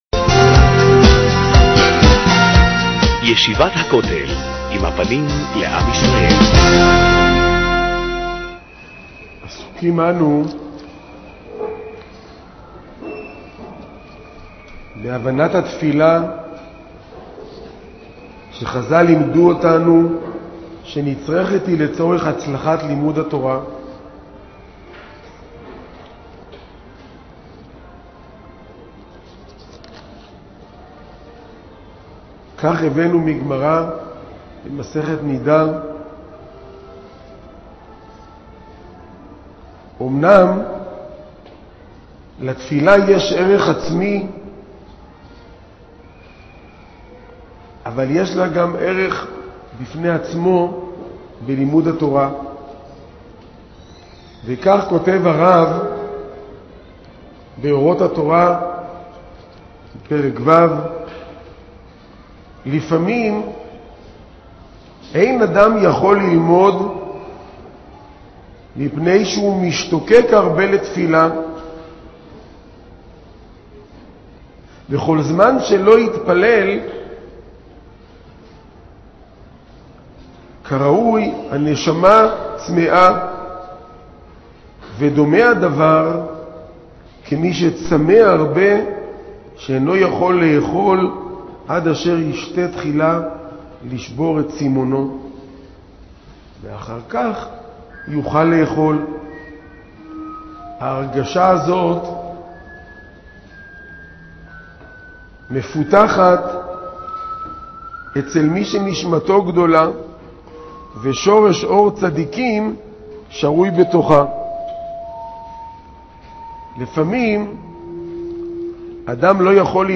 מעביר השיעור: מו"ר ראש הישיבה